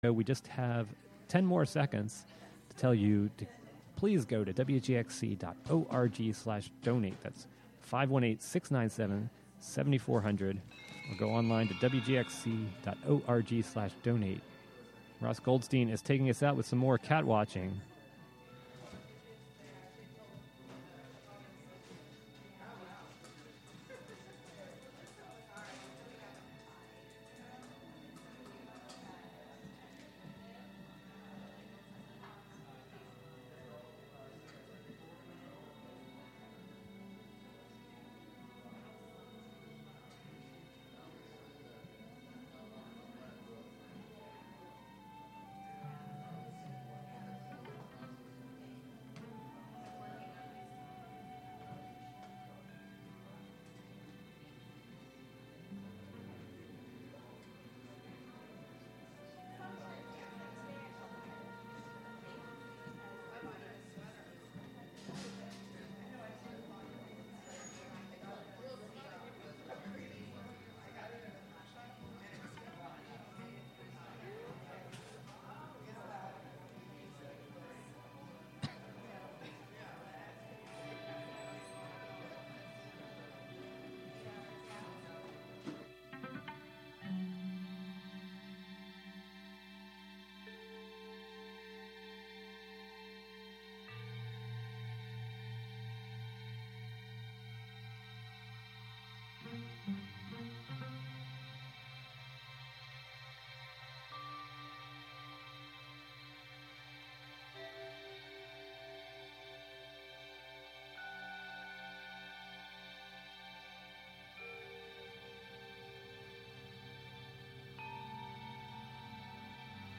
Special Pledge Drive edition of this bi-monthly show serving up rhythm and blues, soul, gospel and funk – the most memorable classics of yesterday, today and tomorrow. Broadcast live from the Catskill Community Center.